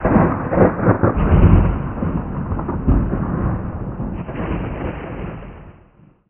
1 channel
ThunderQuietDistant.mp3